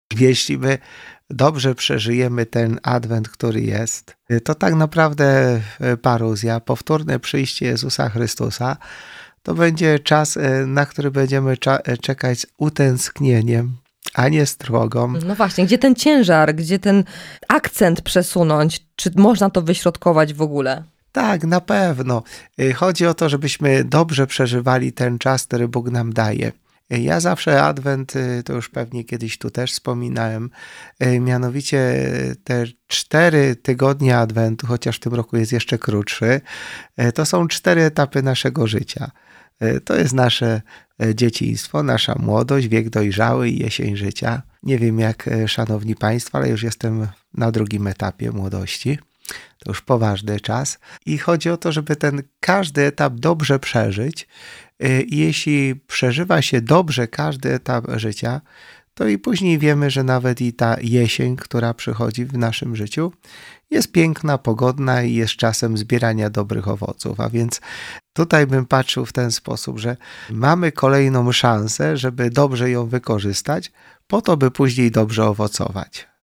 I w jaki sposób Jezus przyszedłby na świat w XXI w.? -pytamy ojca biskupa Jacka Kicińskiego.